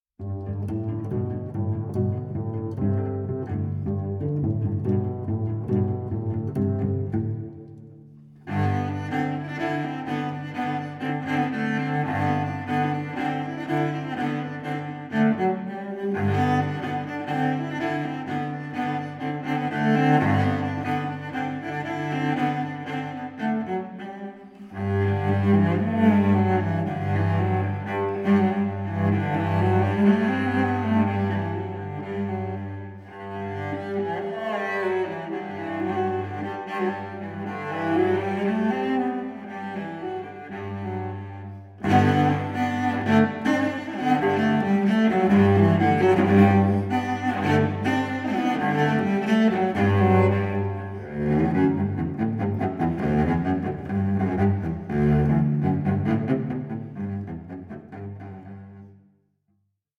cellist
solo work